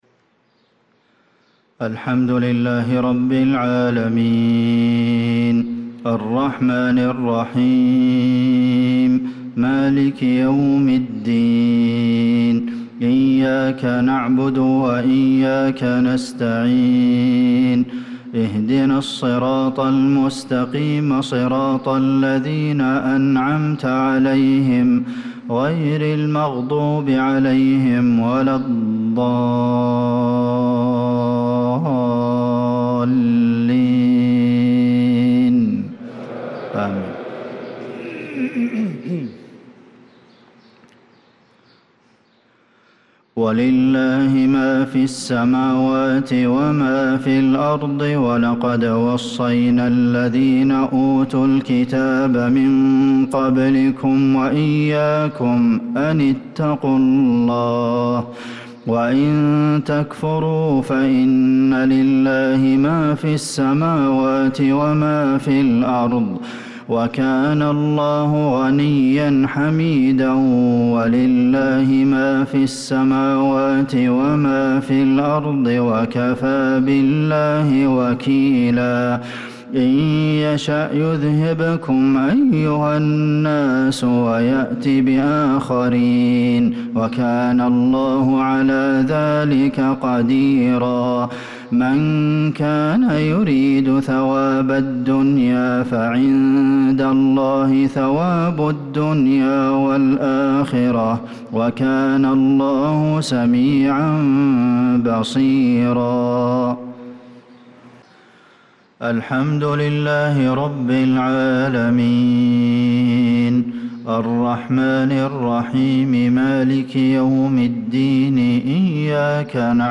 صلاة المغرب للقارئ عبدالمحسن القاسم 11 ذو الحجة 1445 هـ
تِلَاوَات الْحَرَمَيْن .